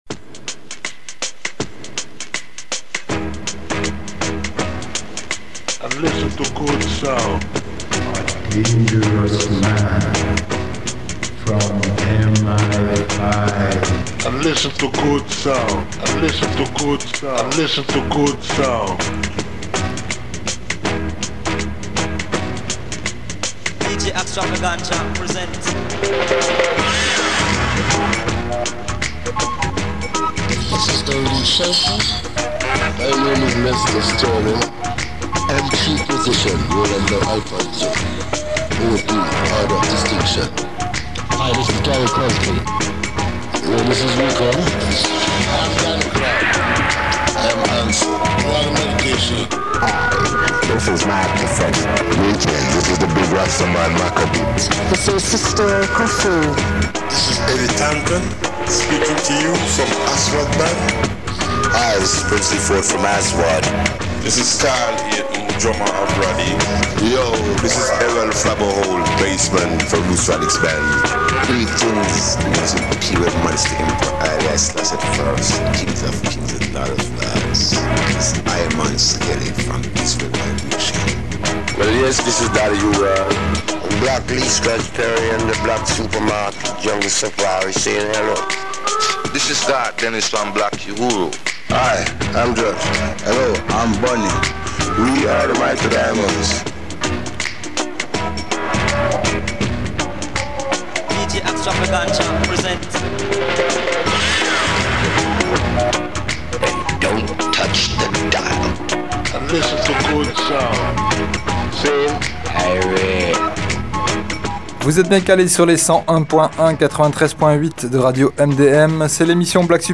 Mercredi 23 et samedi 26 février 2022 (ska reggae)